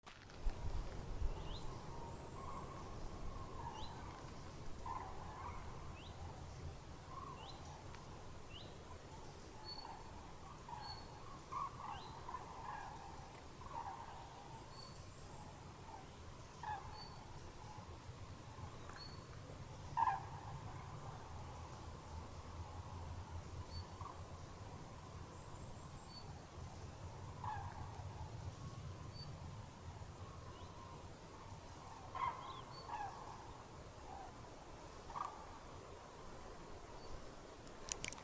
Birds -> Thrushes ->
Common Redstart, Phoenicurus phoenicurus
StatusVoice, calls heard
Notes/ierakstīta balss, iespējams lakstīgala, suga precizējama